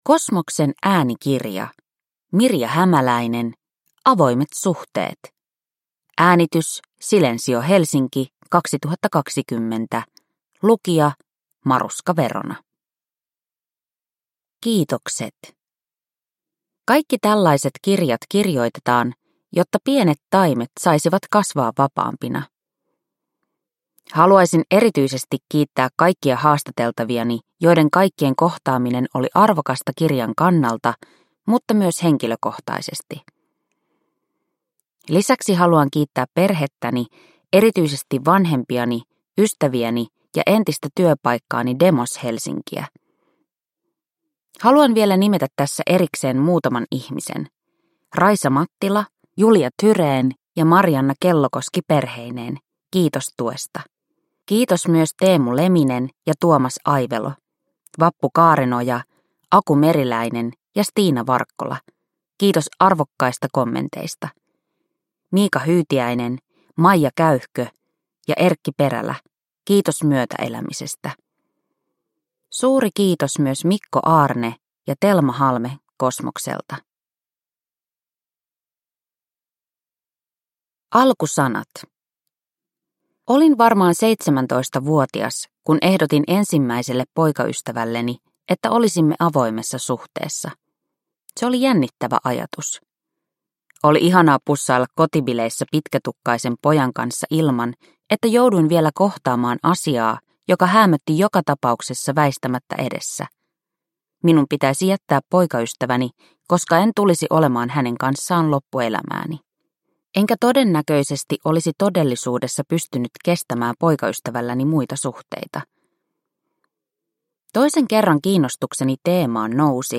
Avoimet suhteet – Ljudbok – Laddas ner